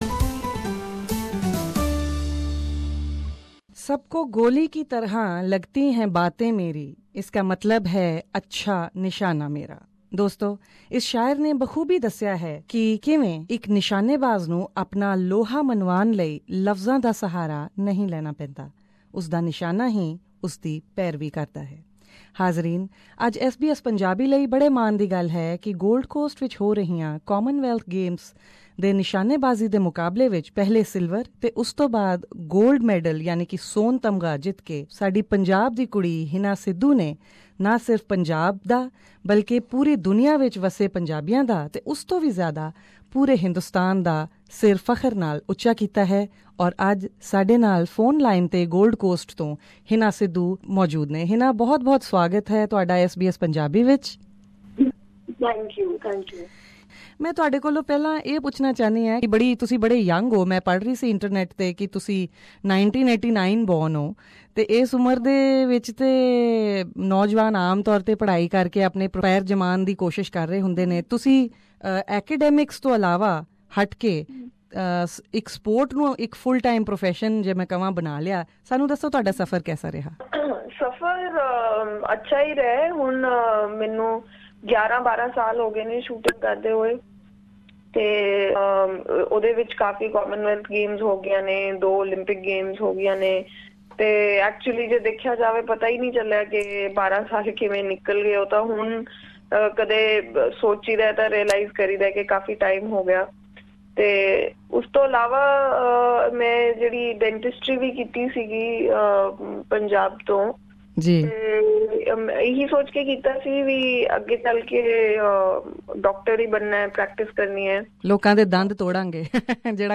Listen to the complete interview with them both, here.